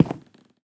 Minecraft Version Minecraft Version 1.21.5 Latest Release | Latest Snapshot 1.21.5 / assets / minecraft / sounds / block / cherry_wood / step5.ogg Compare With Compare With Latest Release | Latest Snapshot
step5.ogg